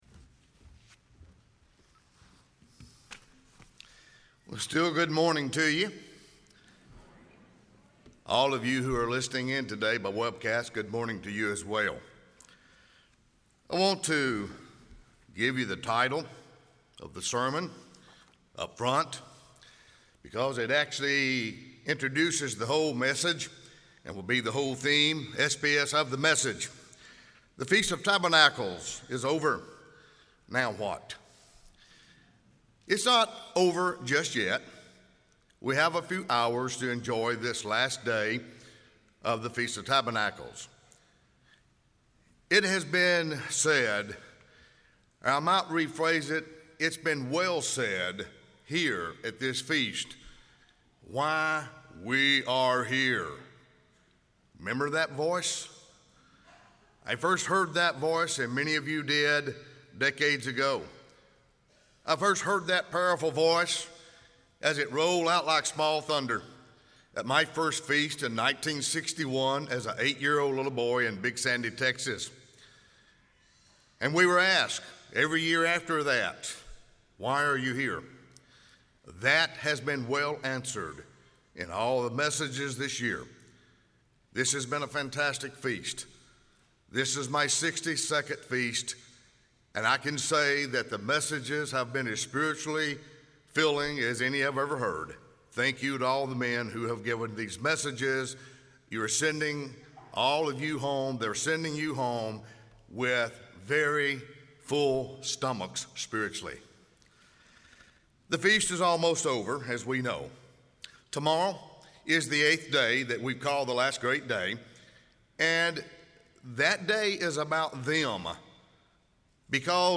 This sermon was given at the Jekyll Island, Georgia 2022 Feast site.